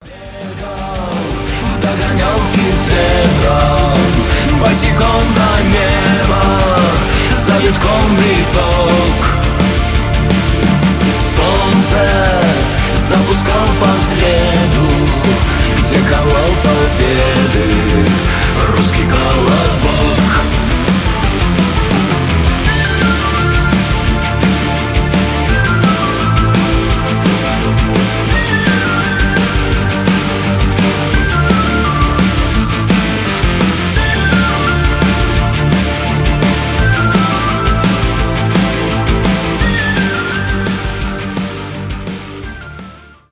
рок , тяжелый рок